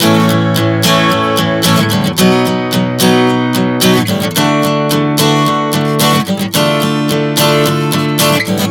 Prog 110 A-B-D-Dm.wav